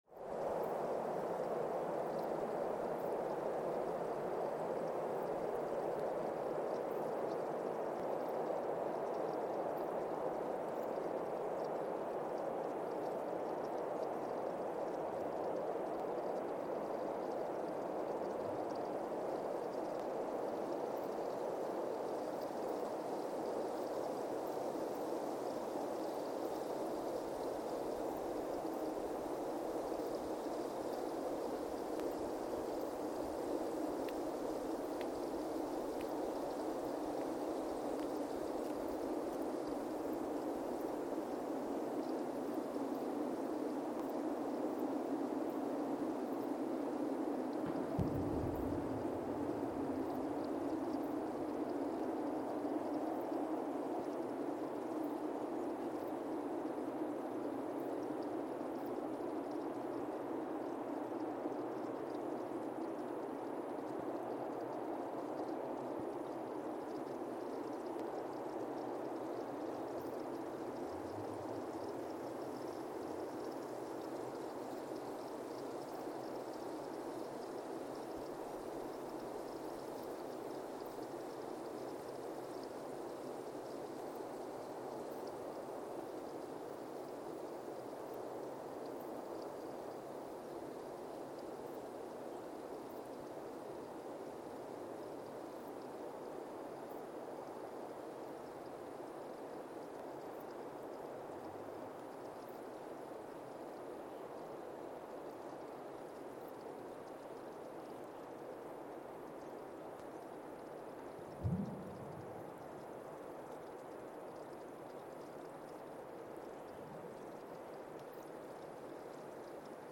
Weston, MA, USA (seismic) archived on December 19, 2024
Sensor : CMG-40T broadband seismometer
Speedup : ×1,800 (transposed up about 11 octaves)
Loop duration (audio) : 05:36 (stereo)
SoX post-processing : highpass -2 90 equalizer 300 2q -6 equalizer 400 2q -6 equalizer 90 12q 6